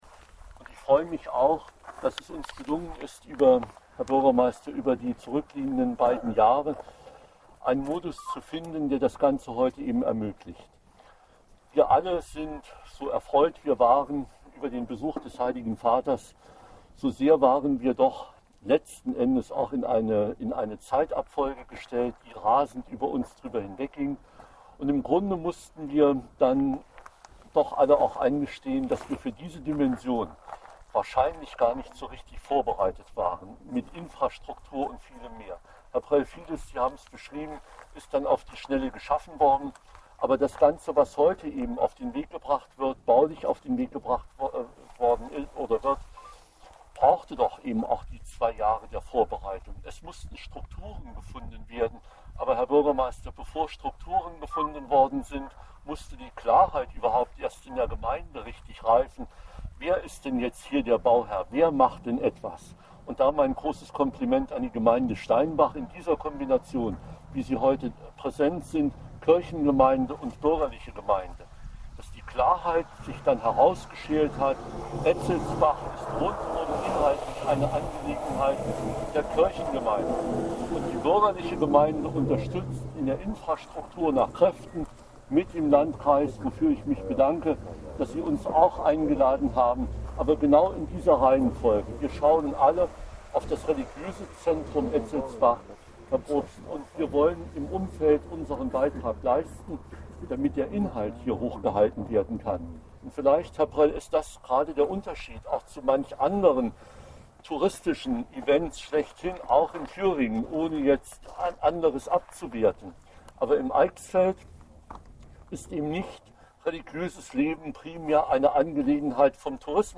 Landrat Werner Henning sagte bei der Grundsteinlegung, dass erst